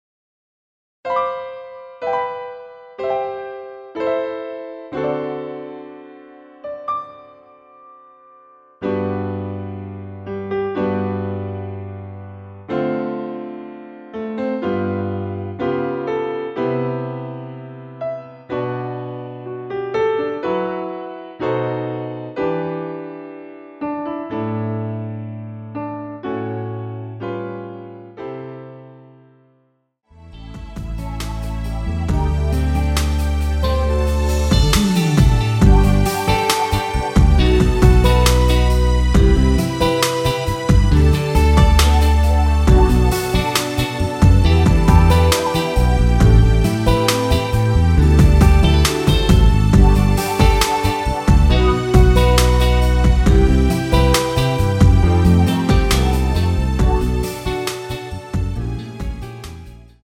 원키에서(+2)올린 MR 입니다.
앞부분30초, 뒷부분30초씩 편집해서 올려 드리고 있습니다.